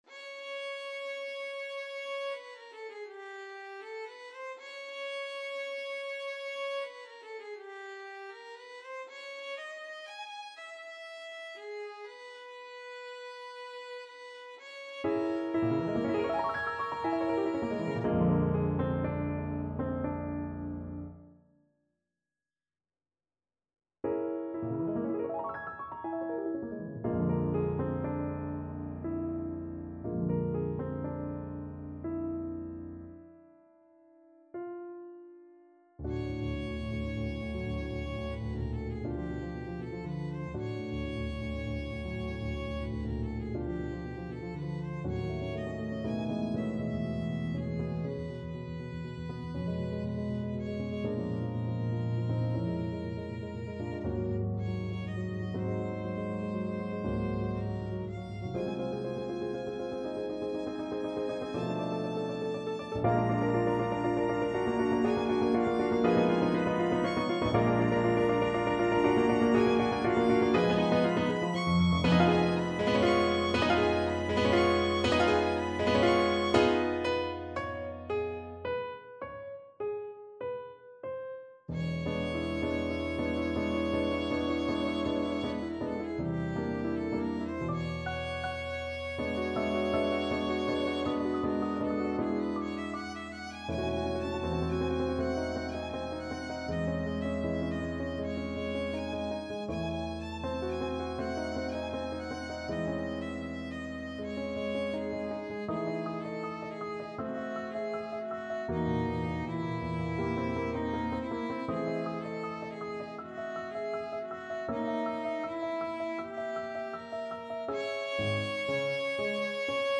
9/8 (View more 9/8 Music)
Classical (View more Classical Violin Music)